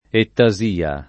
ettasia [ etta @& a ]